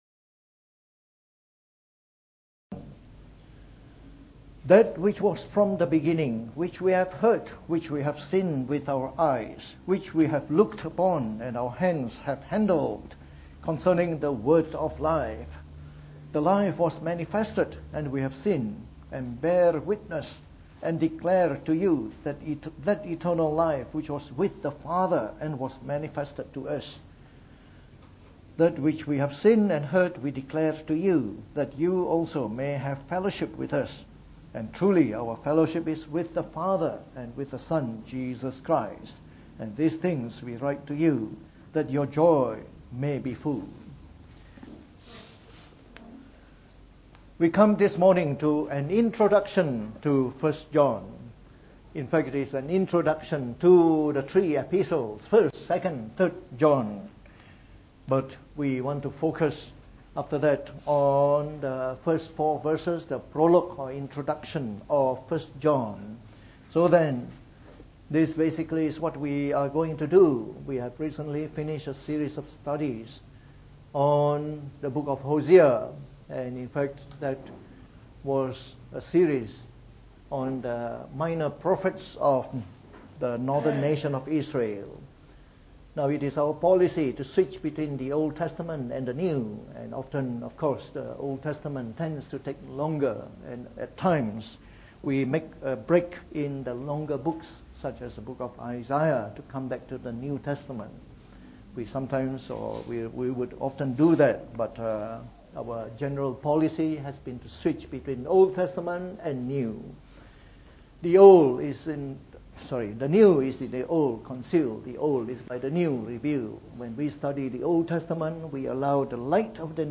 From our series on the Book of 1 John delivered in the Morning Service.